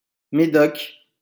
The Médoc (French pronunciation: [meˈdɔk]